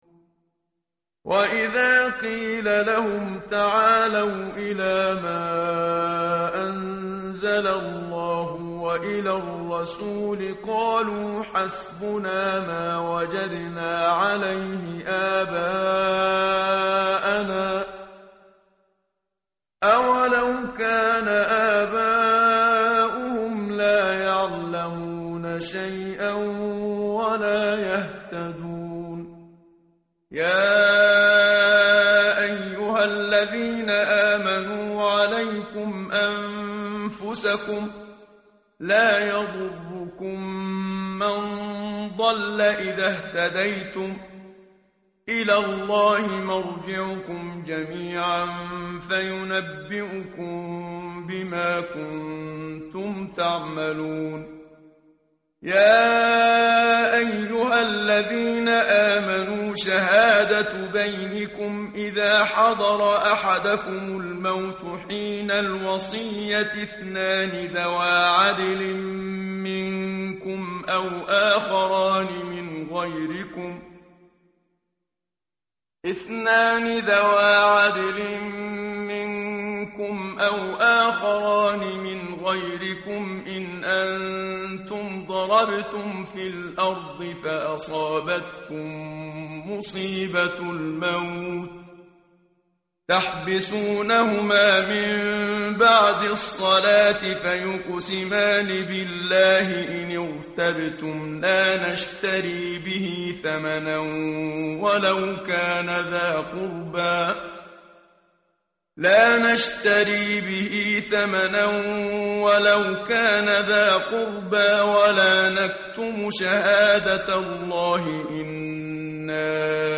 متن قرآن همراه باتلاوت قرآن و ترجمه
tartil_menshavi_page_125.mp3